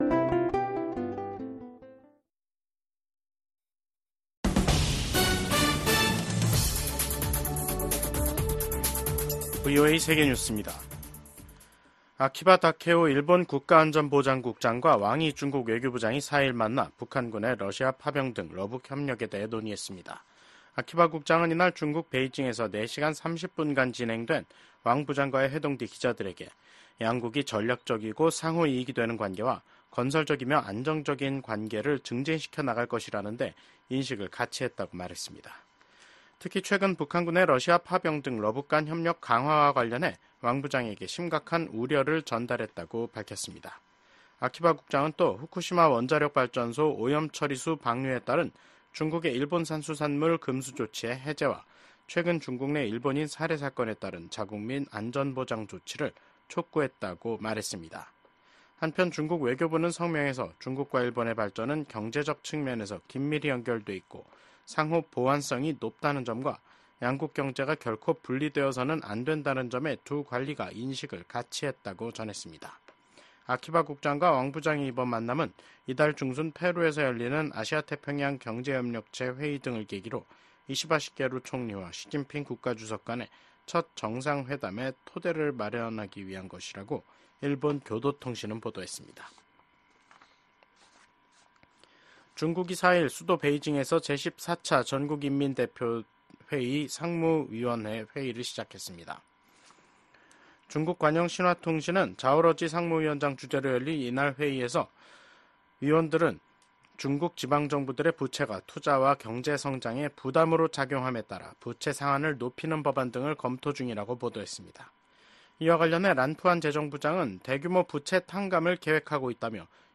VOA 한국어 간판 뉴스 프로그램 '뉴스 투데이', 2024년 11월 4일 3부 방송입니다. 북한의 러시아 파병 문제가 국제적인 중대 현안으로 떠오른 가운데 북러 외교수장들은 러시아의 우크라이나 전쟁을 고리로 한 결속을 강조했습니다. 미국과 한국의 외교, 국방 수장들이 북러 군사협력 심화와 북한의 대륙간탄도미사일 발사를 강력히 규탄했습니다. 10개월 만에 재개된 북한의 대륙간탄도미사일 발사를 규탄하는 국제사회의 목소리가 이어지고 있습니다.